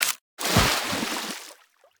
Sfx_creature_trivalve_dive_01.ogg